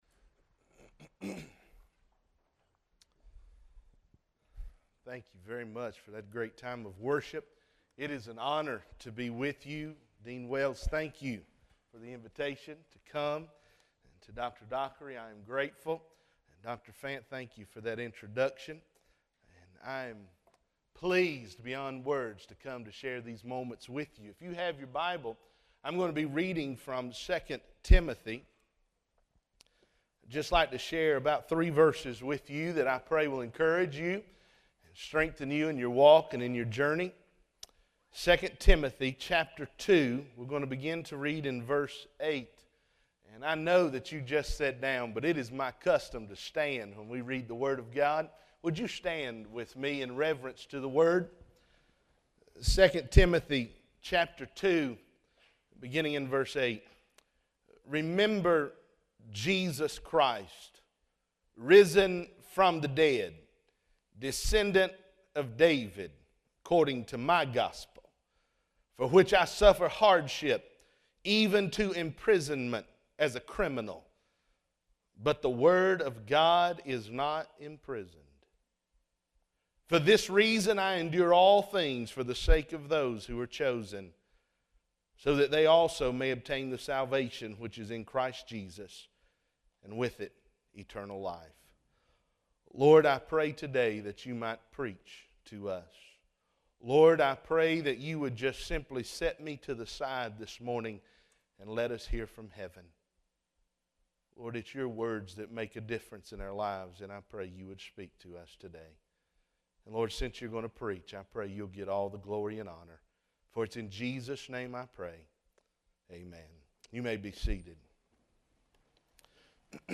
Union University, a Christian College in Tennessee